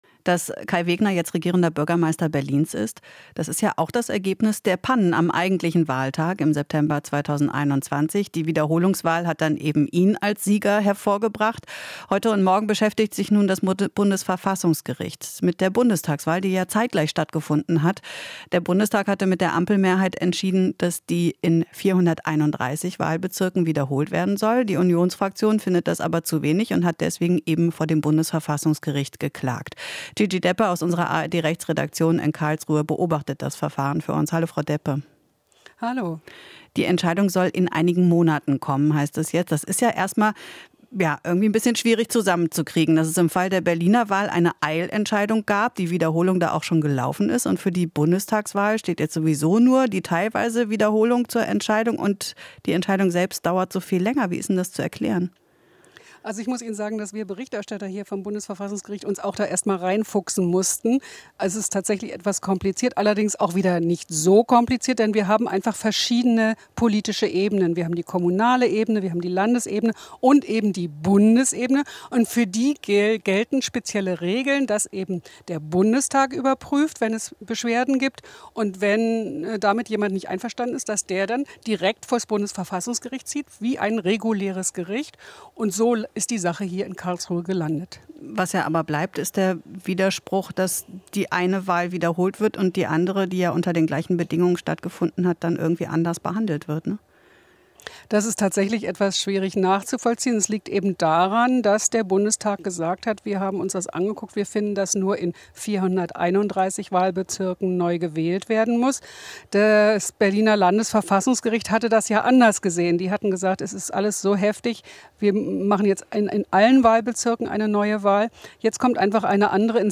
Interview - ARD-Rechtsexpertin: Komplette Neuwahl wird es nicht geben